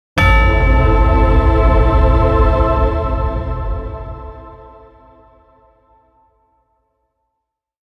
Ghost of the Christmas Past: A Haunting Sound Effect
Dramatic bell chime at midnight. One bell strike, with choirs heard in the background and a deep ambient sound. Bell sounds.
Genres: Sound Effects
Ghost-of-the-Christmas-past-sound-effect.mp3